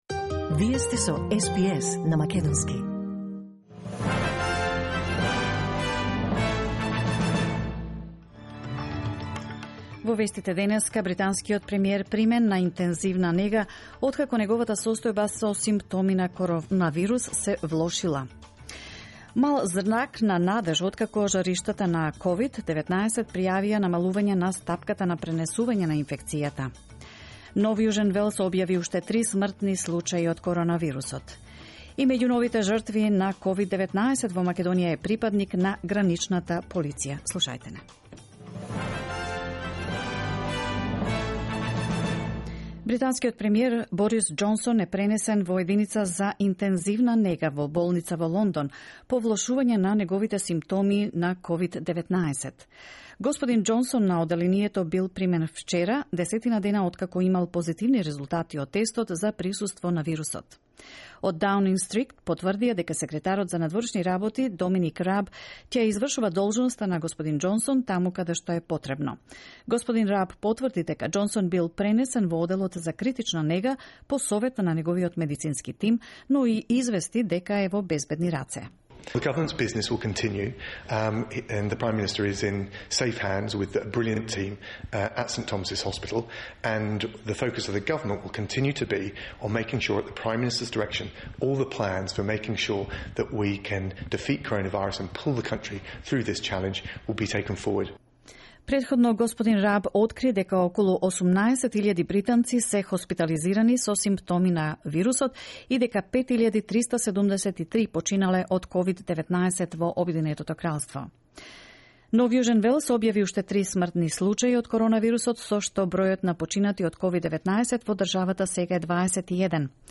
News on SBS in Macedonian